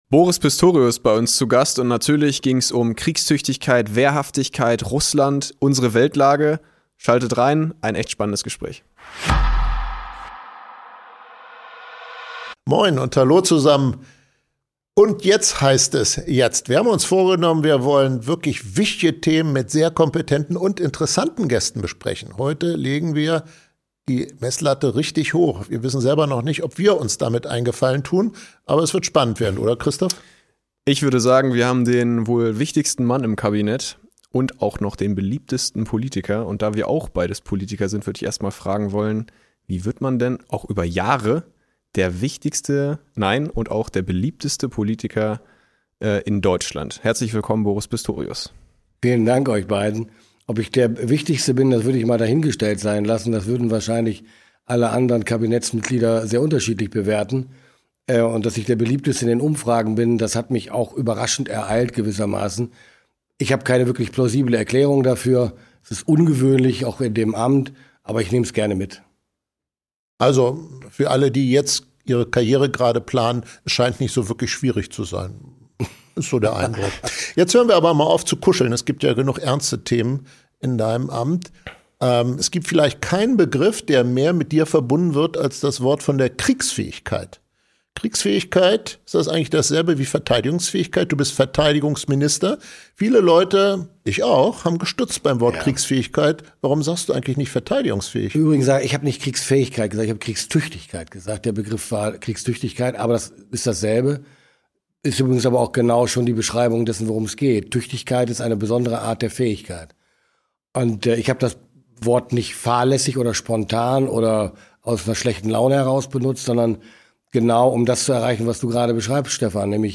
In der 1. Folge „…und jetzt?“ spricht Verteidigungsminister Boris Pistorius über Aufrüstung, Abschreckung und die Frage, ob Europa sich sicherheitspolitisch neu erfinden muss. Wie real ist eine militärische Bedrohung?
Ein intensives Gespräch über Macht, Verantwortung und die unbequeme Erkenntnis, dass Frieden heute nicht mehr selbstverständlich ist.